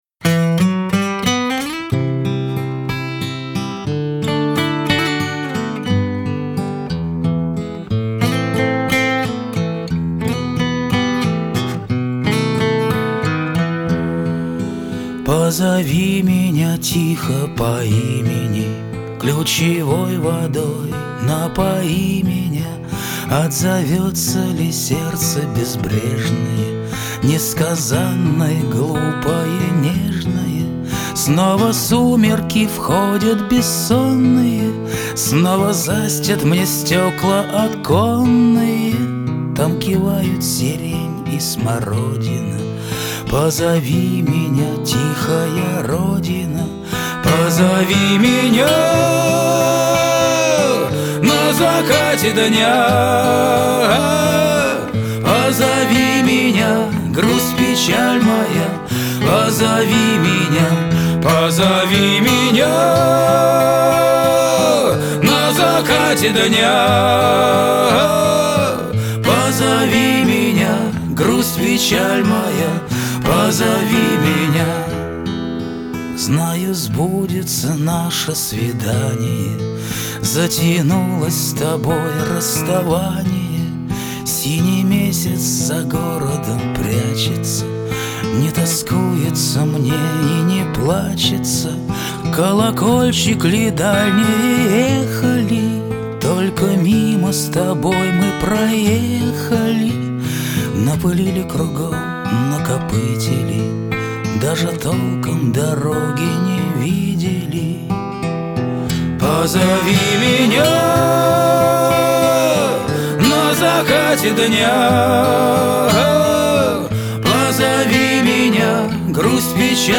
缓缓的曲调， 醇厚的男中音